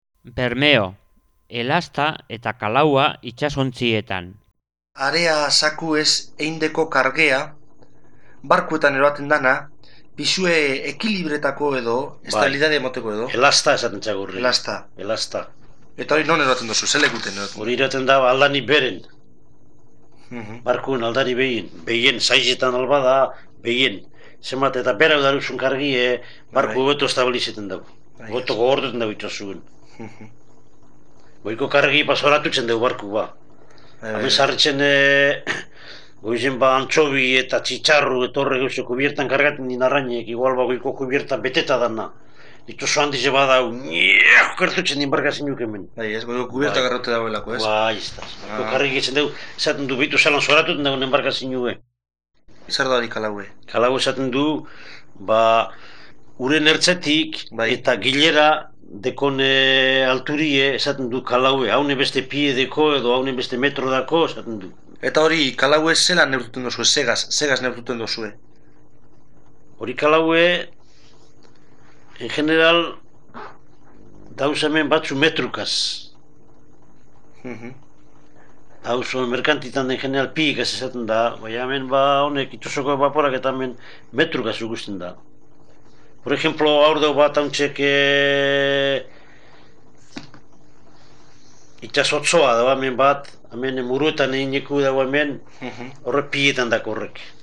1.20. BERMEO
Bermeo.mp3